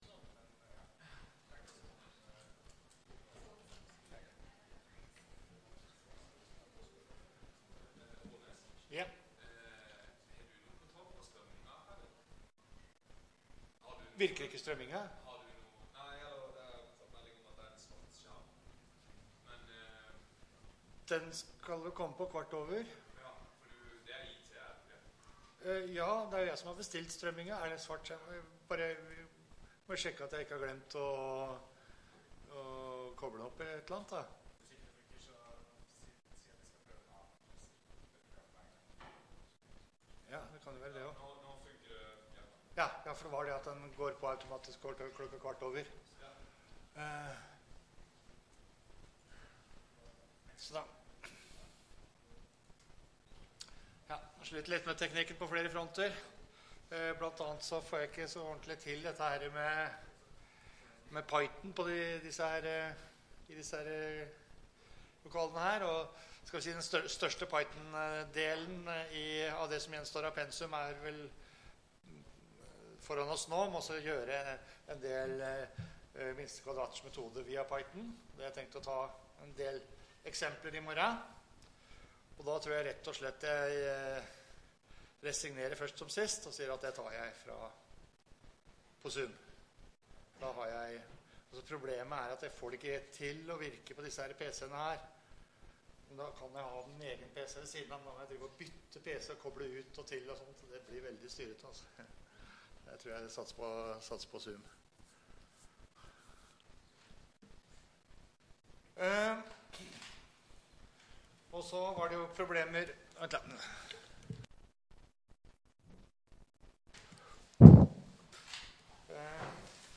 Rom: Lille Eureka, 1/3 Eureka